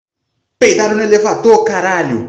Play, download and share peidaro no elevadô caraleo original sound button!!!!
peidaro-no-elevador.mp3